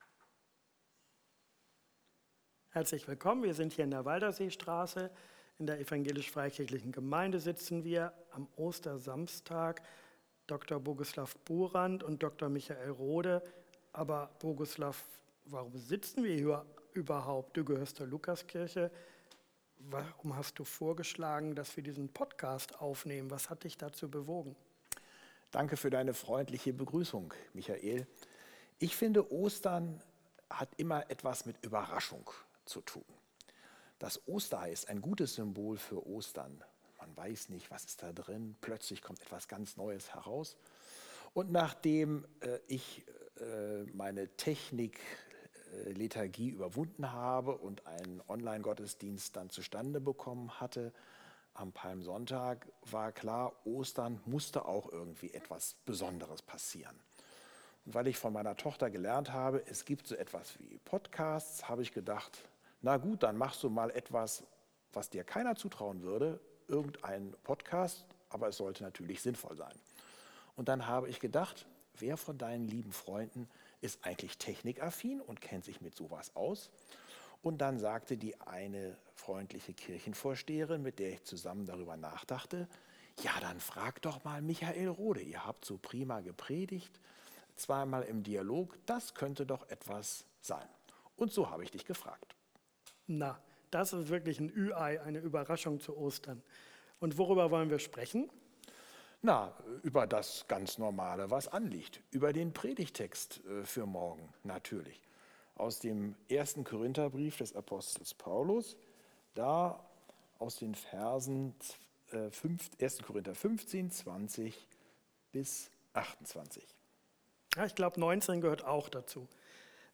Ökumenisches Predigtvorgespräch zu Ostern